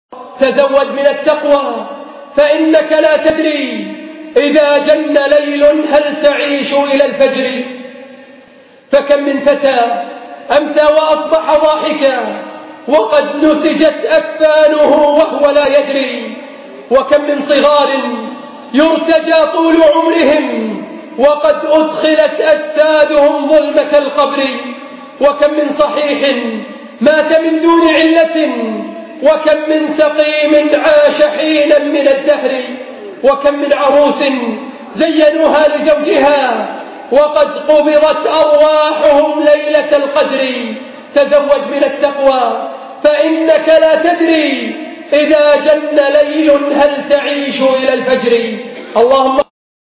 مقتطف من خطبة الاعتبار بتعاقب الليل والنهار